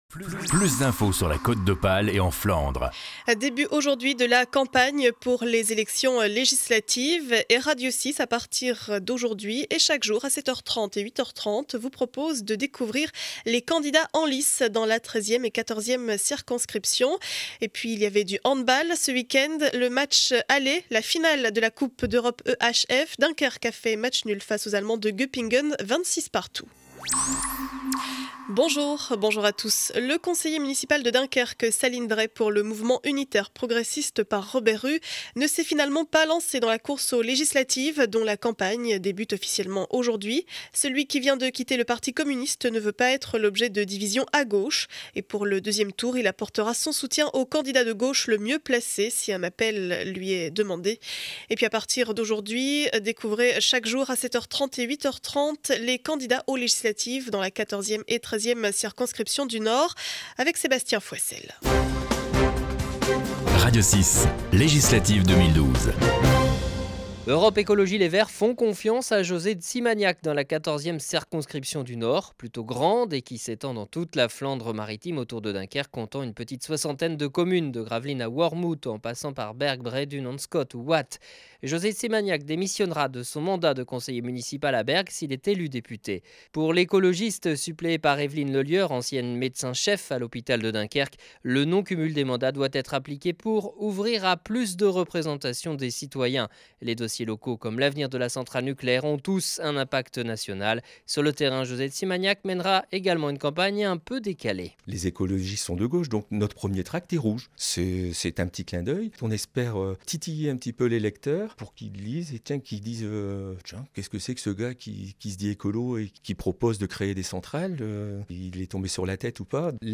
Journal du lundi 21 mai 2012 7 heures 30 édition du Dunkerquois